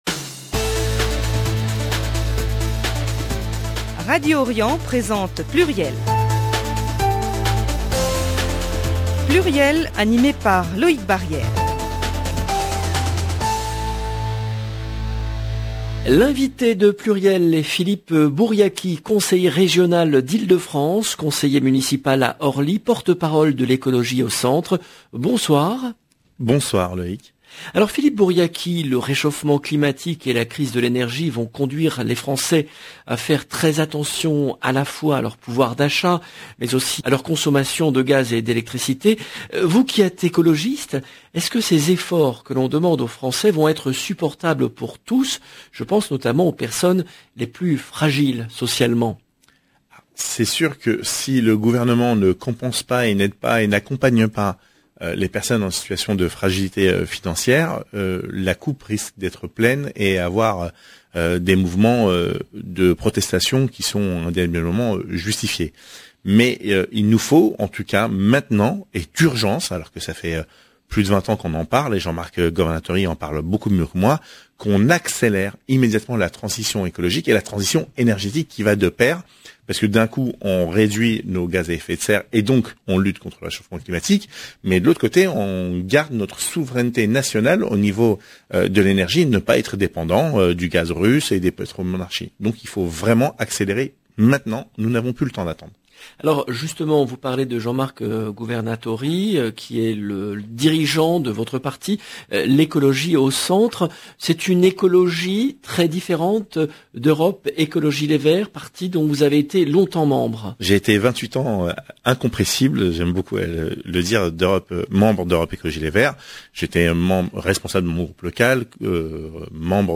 L’invité de PLURIEL mardi 20 septembre 2022 était Philippe Bouriachi, conseiller régional d’Ile-de-France, conseiller municipal à Orly, porte-parole de L’Ecologie au Centre